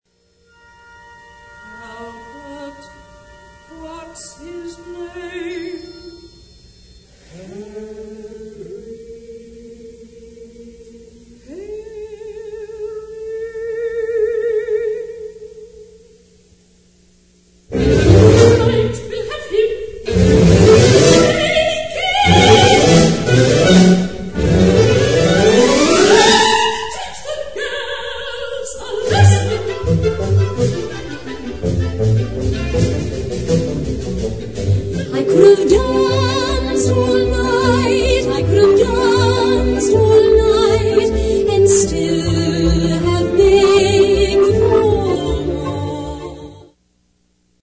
fugue intro